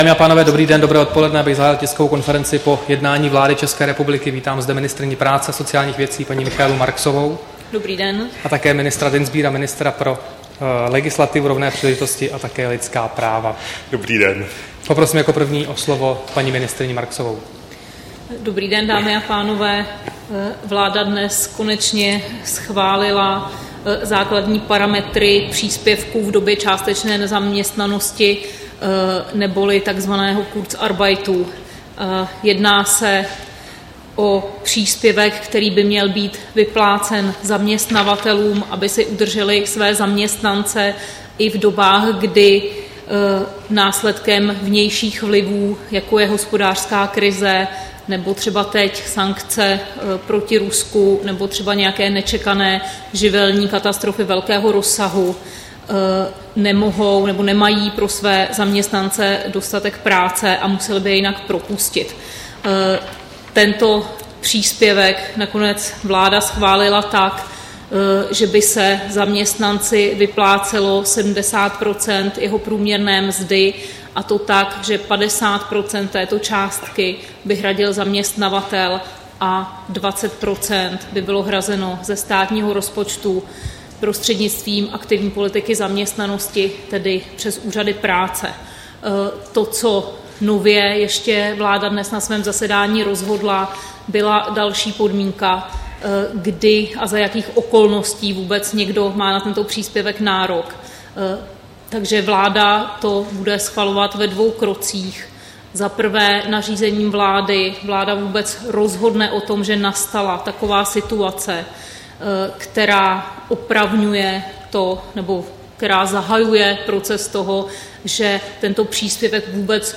Tisková konference po jednání vlády, 3. listopadu 2014